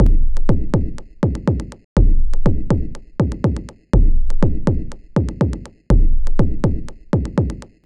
• percussion synth massive 1 Fm.wav
Oscar Mulero, Exium, Go Hyiama, Rene Wise type, clicky and clear sound techno kick and percussion artefacts.